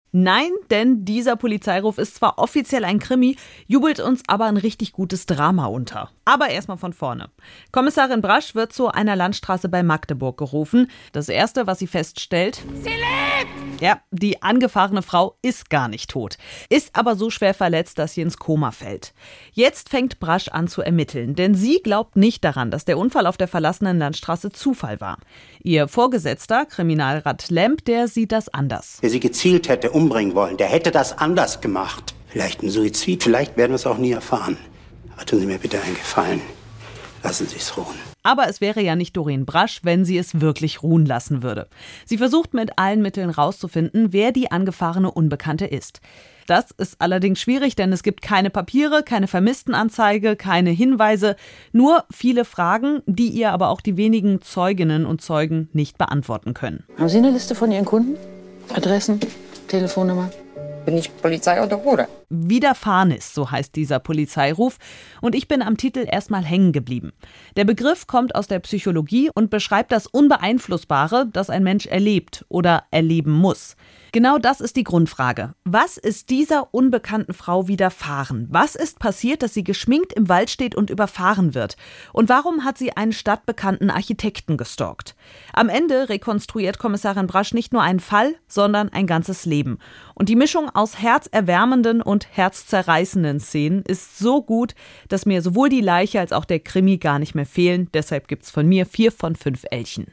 Audio Polizeiruf-Kritik zu „Widerfahrnis“ aus Magdeburg